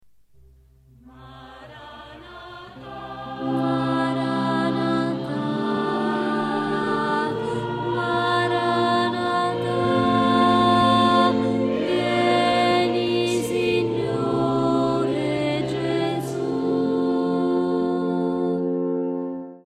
contralto.mp3